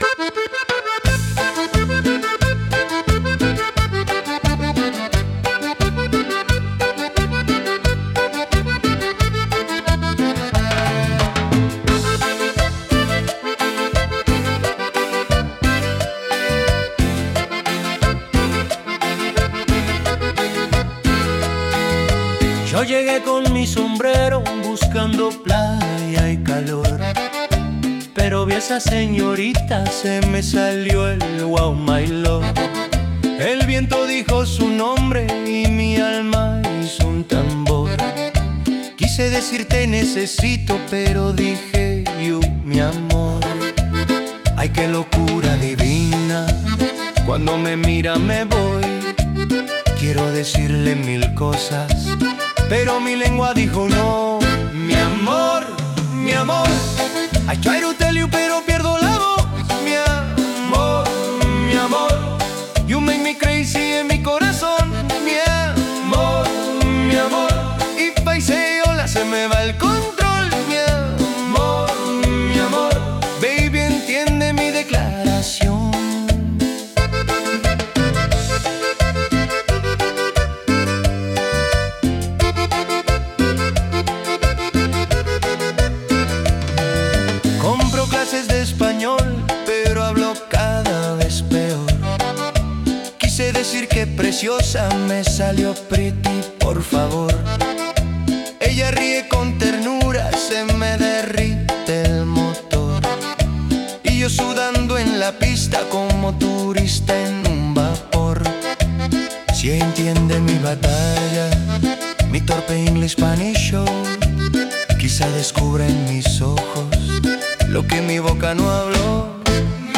A soulful journey of love and connection